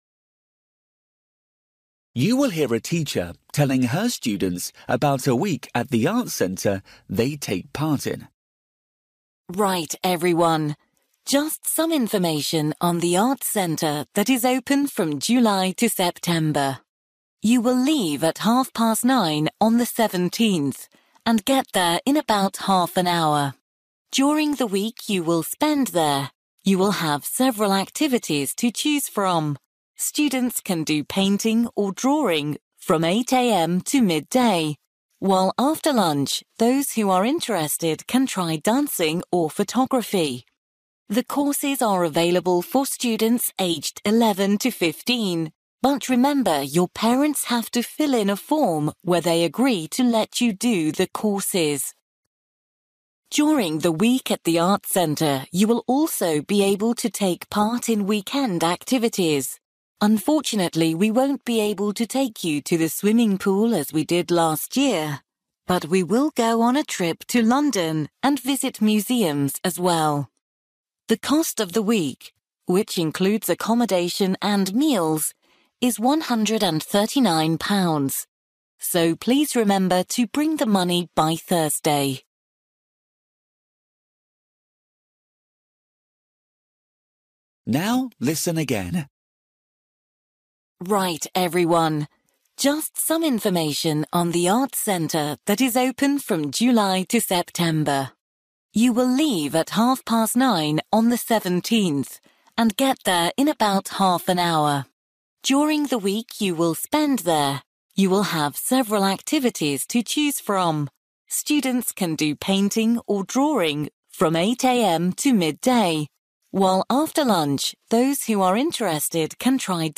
You will hear a teacher telling her students about a week at the Arts Centre they take part in.